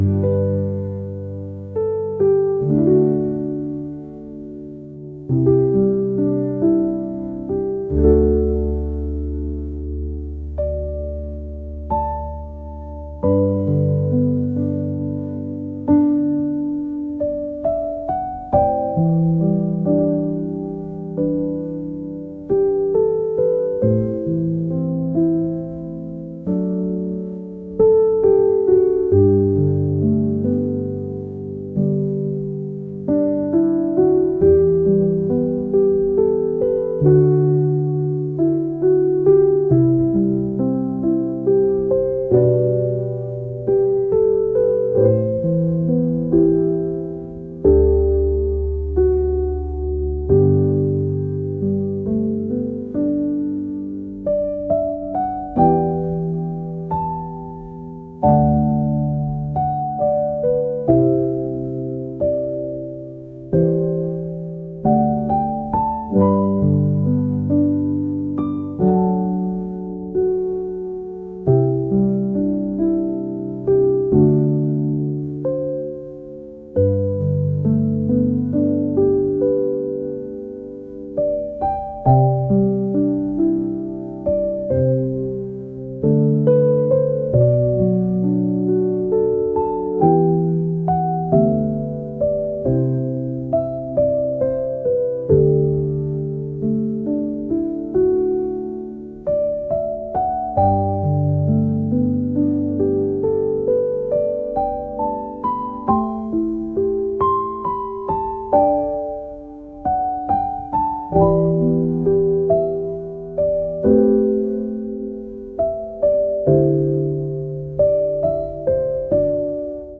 ambient | cinematic | indie